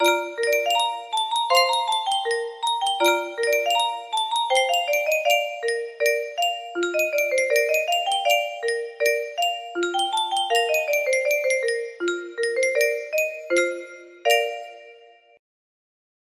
Yunsheng Music Box - Maurice Ravel Bolero Y299 music box melody
Full range 60